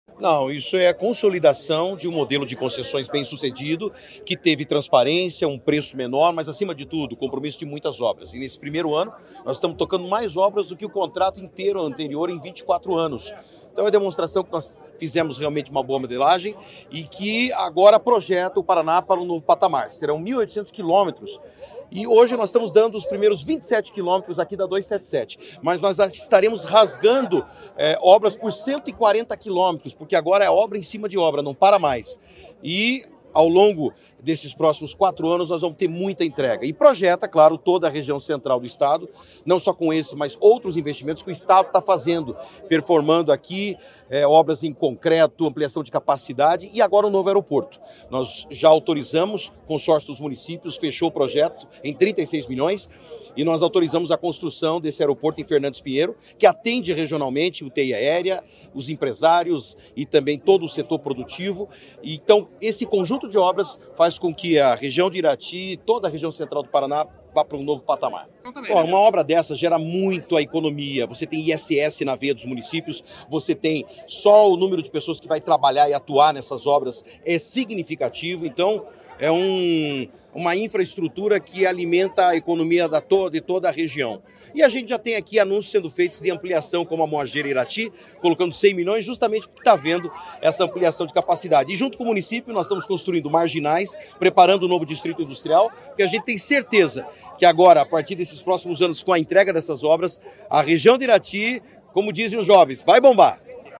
Sonora do secretário da Infraestrutura e Logística, Sandro Alex, sobre o início da duplicação da BR-277 em Palmeira e Irati